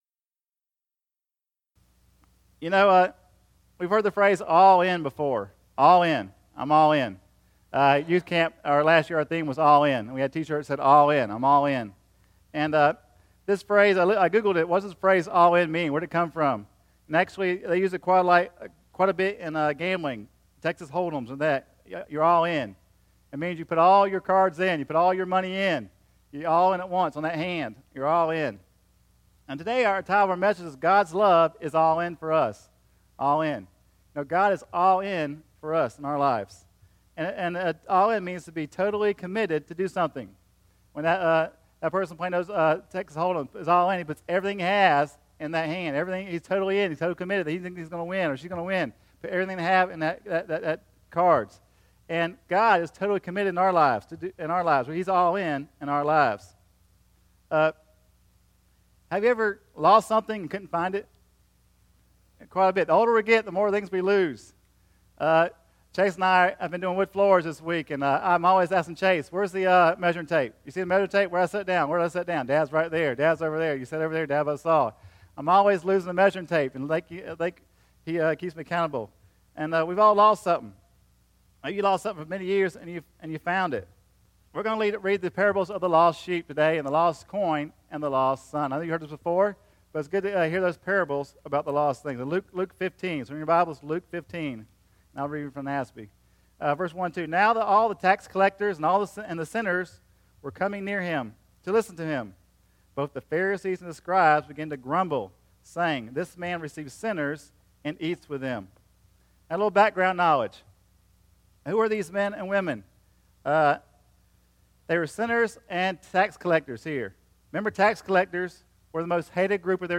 Sermons - Parkland Baptist Church